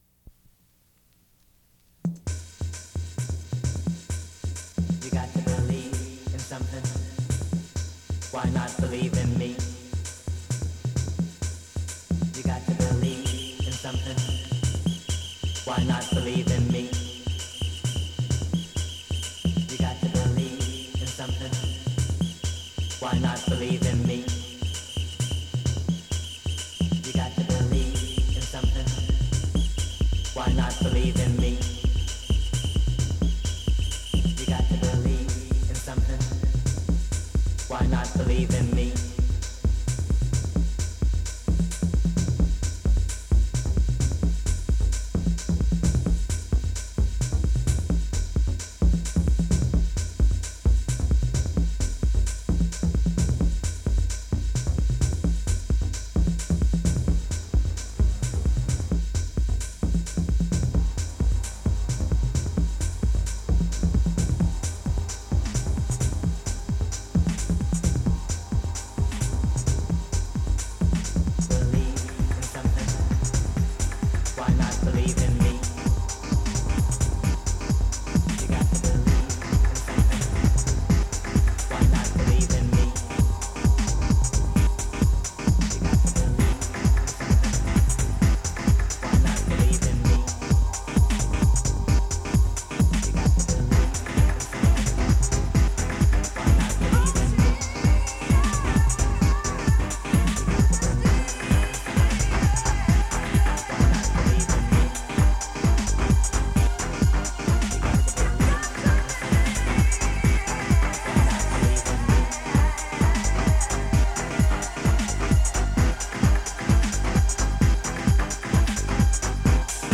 test mix